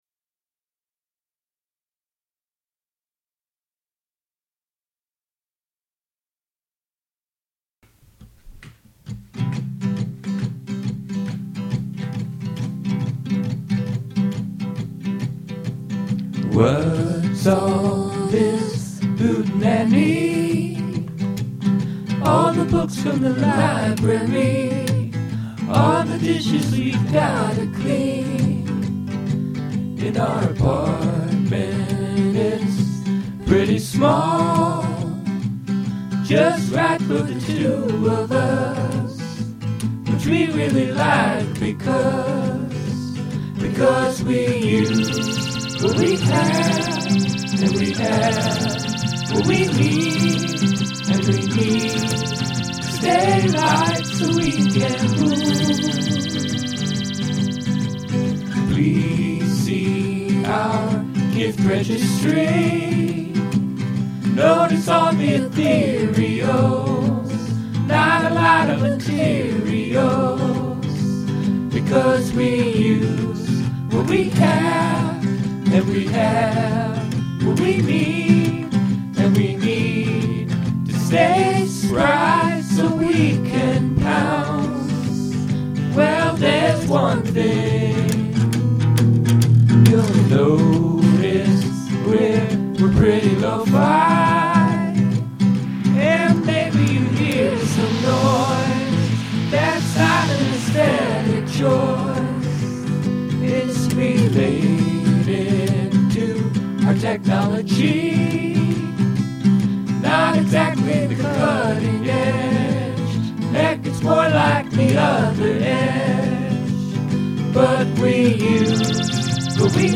verse: C, Am, G
chorus: F, C, F, C, F, C, G
verse, verse, chorus, verse, chorus, verse, verse, chorus, chorus
I love all the layering, how each of you does two different octaves.
Finally, kazoos are always welcome.
The guitar sound is sublime.
So superbly lo-fi and awesome.